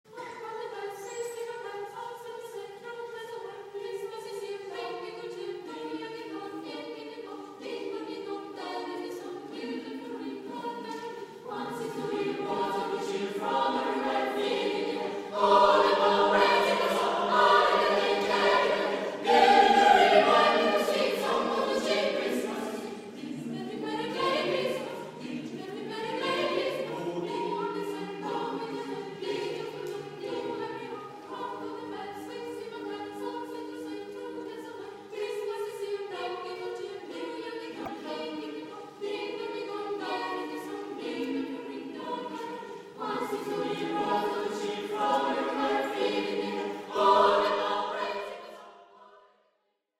Pop. ucraino M. Leontovich